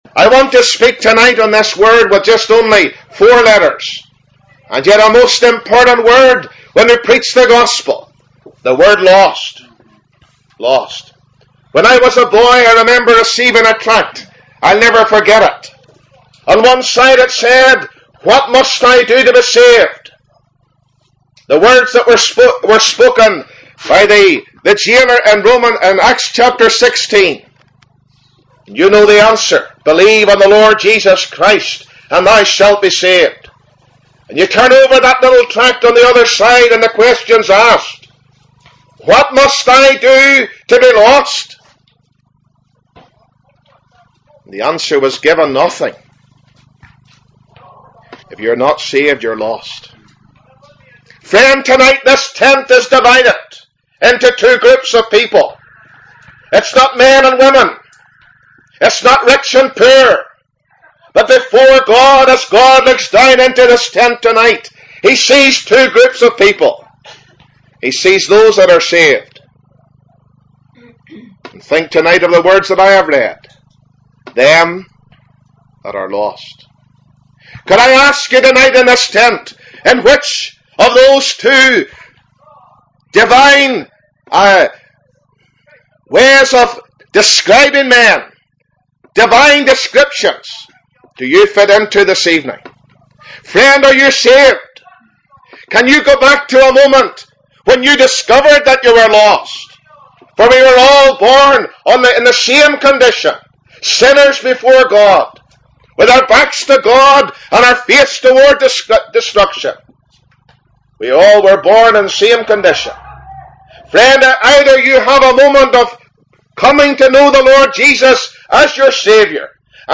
Gospel messages preached 7th August 2007